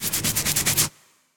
scratch.ogg